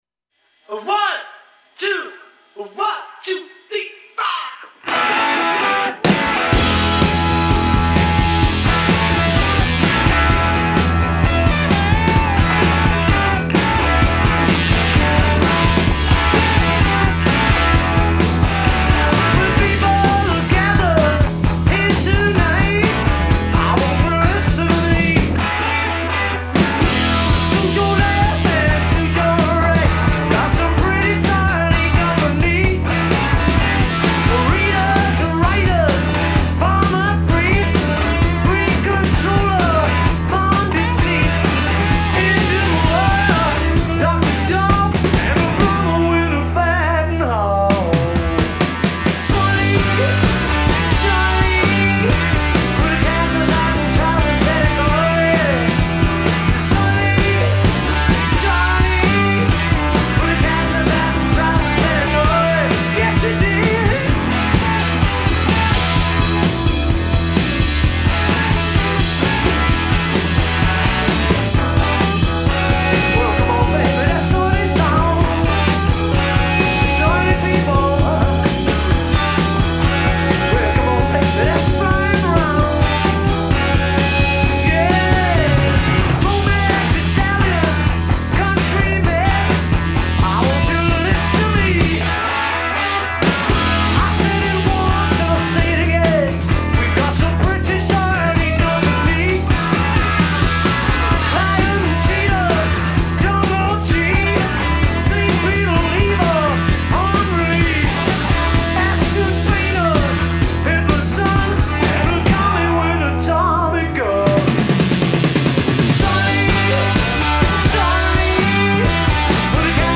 live-in-the-studio
obscure rocker